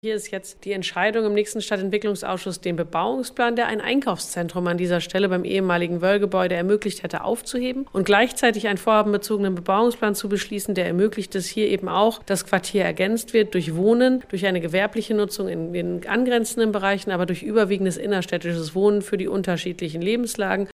(LR) Der Ausschuss für Stadtentwicklung und Umwelt der Stadt Osnabrück berät am Donnerstag über die Planungen für die Johannishöfe. Durch Änderungen im Bebauungsplan sollen die Grundlagen für das urbane Quartier zwischen Neumarkt und Johannisstraße gelegt werden, erklärt Oberbürgermeisterin Katharina Pötter.